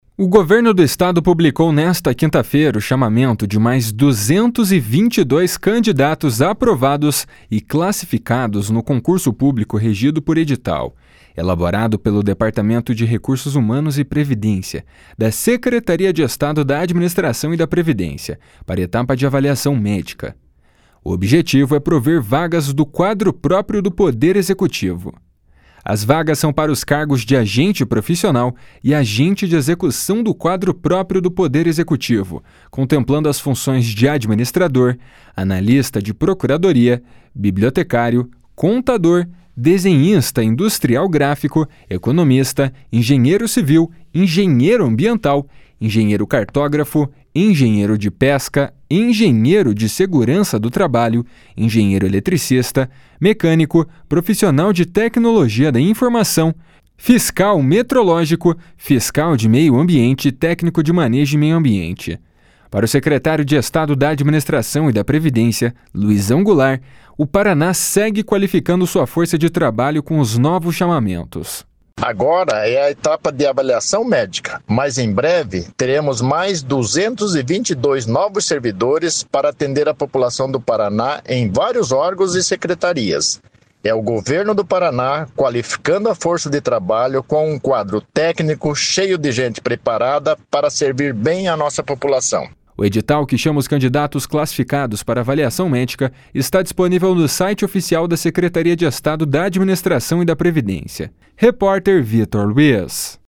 // SONORA LUIZÃO GOULART //